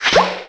Mario 64 sound effects
pokeoutofsand.WAV